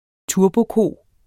Udtale [ ˈtuɐ̯boˌkoˀ ]